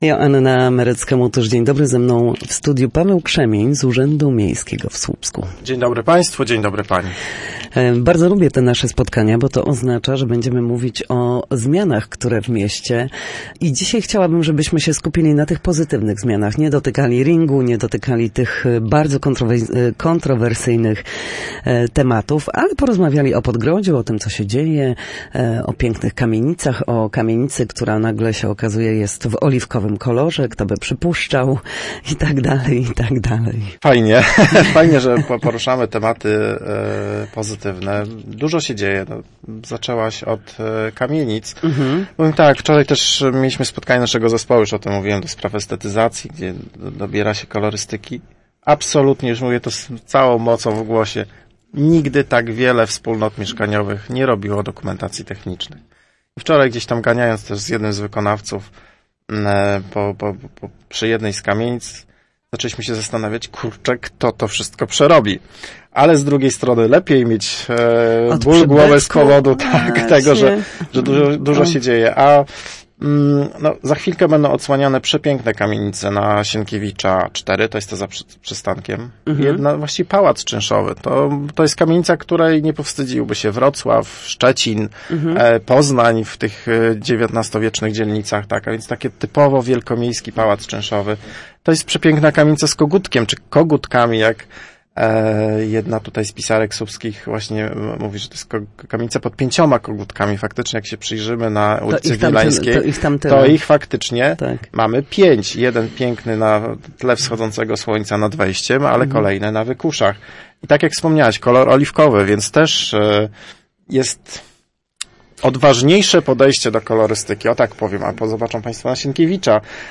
Do 18 listopada można korzystać z konsultacji dotyczących Gminnego Programu Rewitalizacji Słupska. Na antenie Studia Słupsk